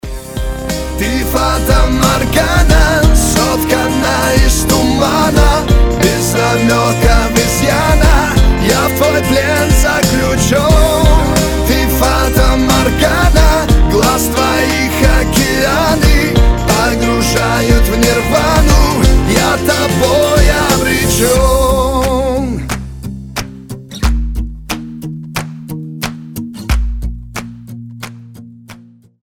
• Качество: 320, Stereo
гитара
красивый мужской голос